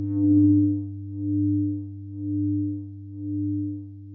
描述：glitch , squeek, , idm
标签： squeek idm glitch
声道立体声